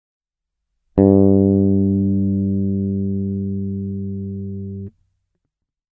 Fretless Bass » Fretless bass, open E, bridge pickup
描述：Harley Benton B550FL fretless bass with Sadowsky SBF40B Flatwound 40125 strings. Recorded directly, no effects. Open E string, bridge pickup.
标签： bassguitar bass fretless singlenote fretlessbass
声道立体声